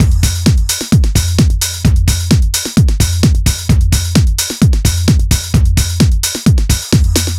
NRG 4 On The Floor 023.wav